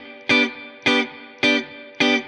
DD_StratChop_105-Gmaj.wav